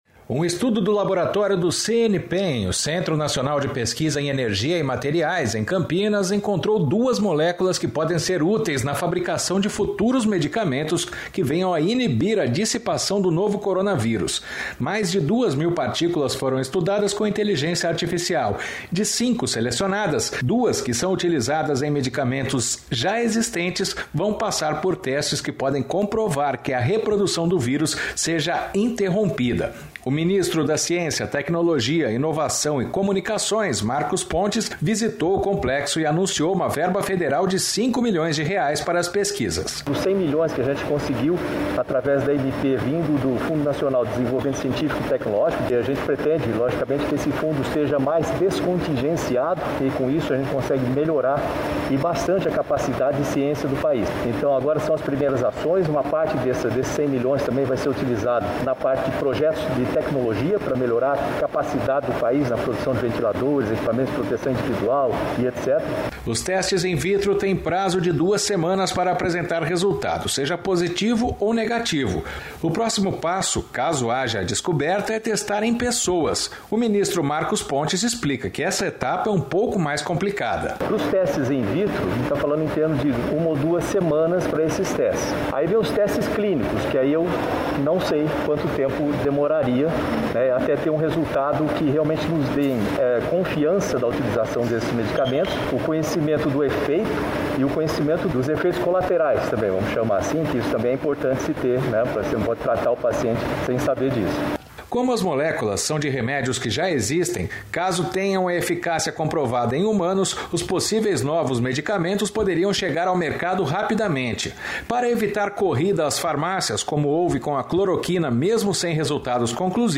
O ministro Marcos Pontes explica que essa etapa é um pouco mais complicada.